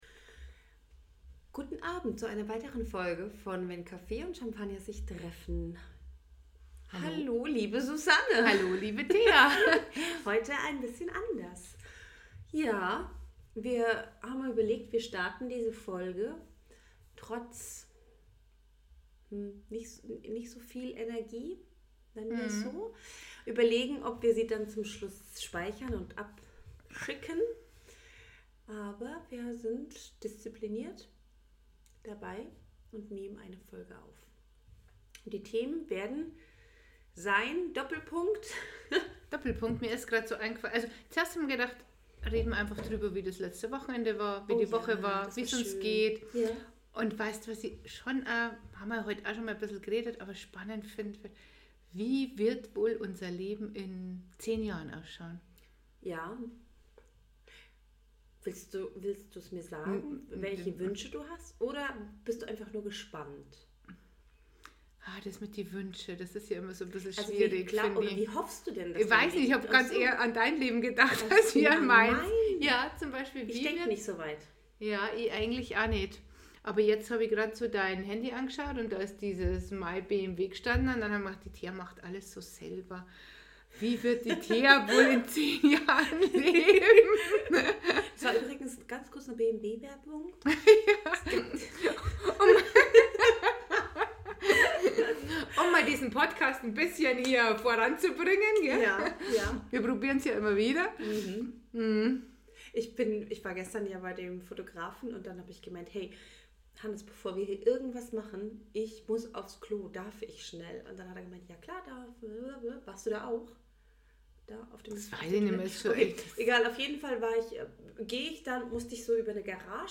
Erweiterte Suche Denn sie wussten nicht, was sie sagten. vor 1 Monat Wenn sich zwei Frauen treffen. 44 Minuten 0 0 0 0 0 0 Podcast Podcaster Wenn Kaffee und Champanger sich treffen Zwei Frauen die dich in ihre Gedanken mitnehmen.